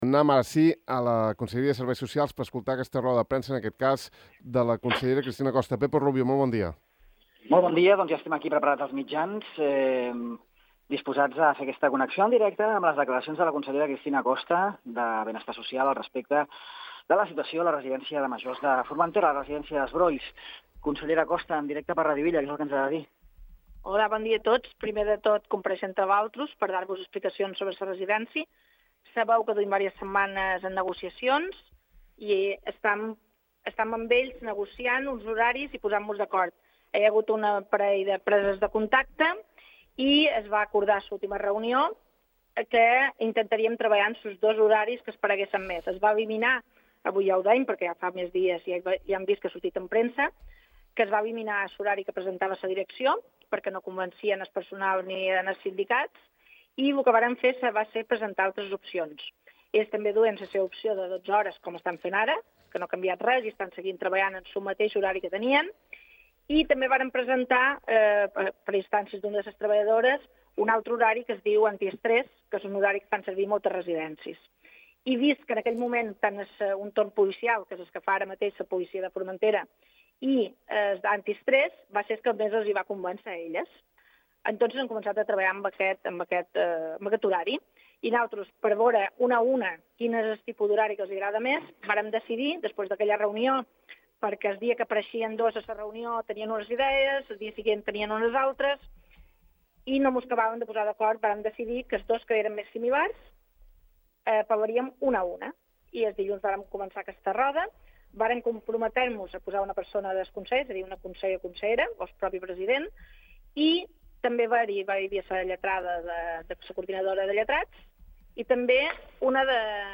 La consellera de Benestar Social, Cristina Costa, ha informat aquest dimecres de la situació en la qual es troba actualment la negociació amb les treballadores de la Residència de Majors des Brolls. Podeu escoltar la roda de premsa en el següent reproductor.